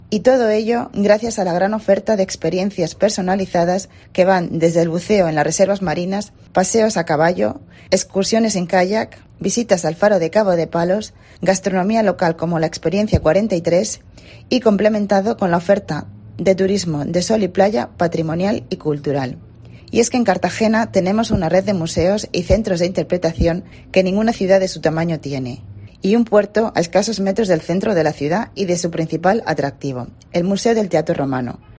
en la feria de Londres